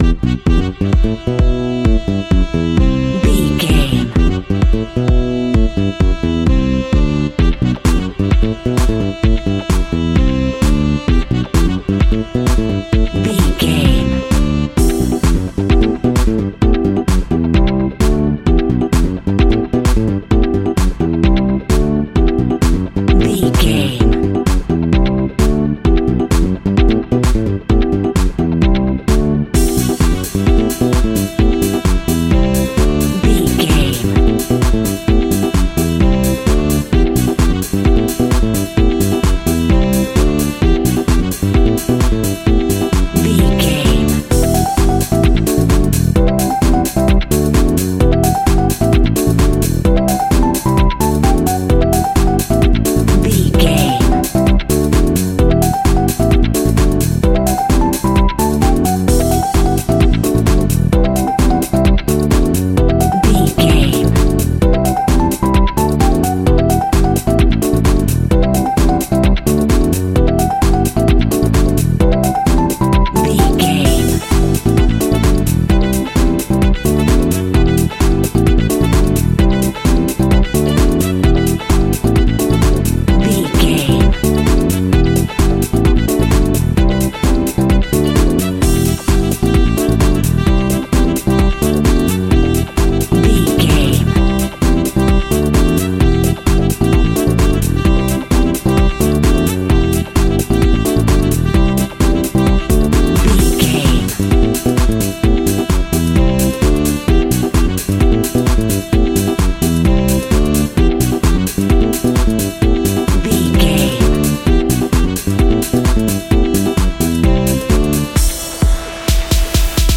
Aeolian/Minor
F#
uplifting
energetic
funky
saxophone
bass guitar
drums
synthesiser
electric organ
funky house
disco
upbeat